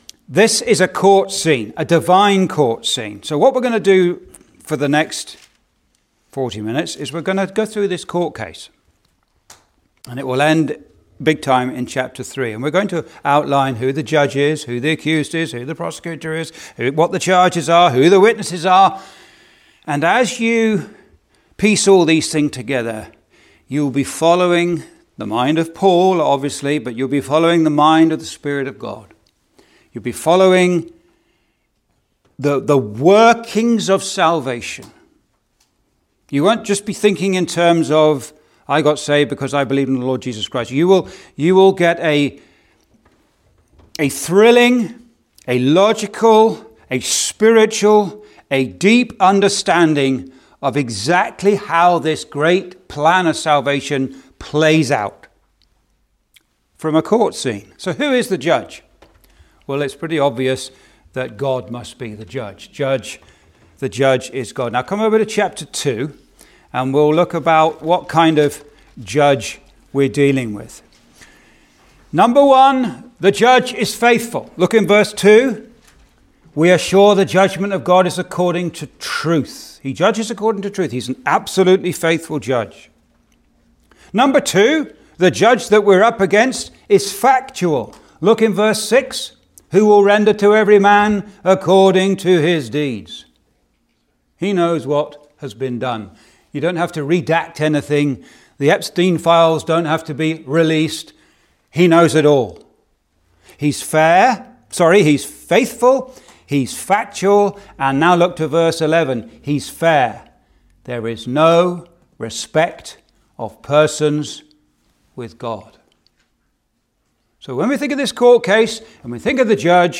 (Recorded in Thunder Bay Gospel Hall, ON, Canada on 12th Nov 2025)